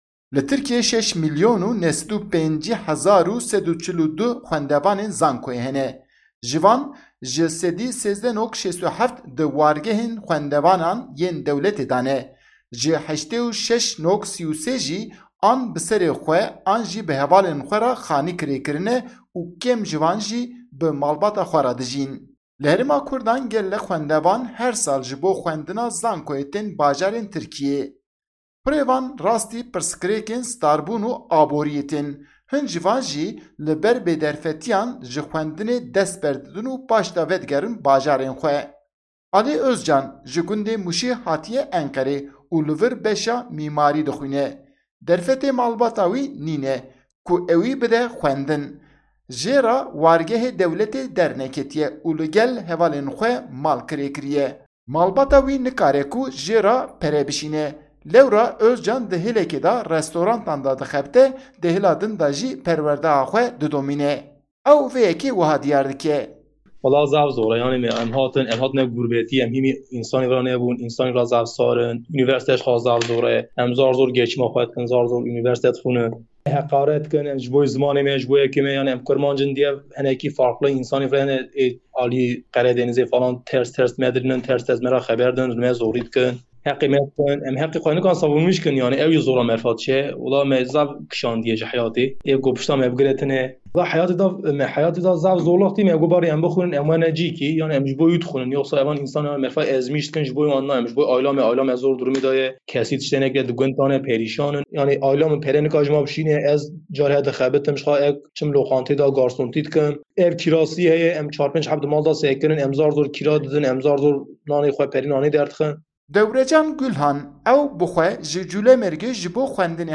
Raporta Deng